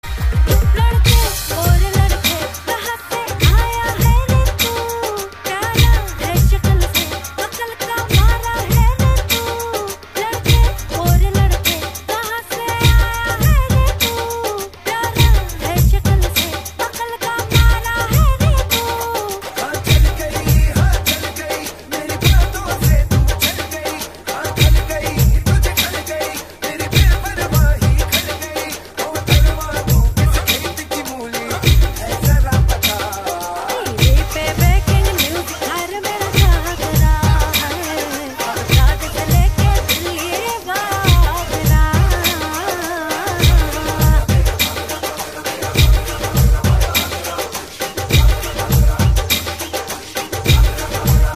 File Type : Navratri dandiya ringtones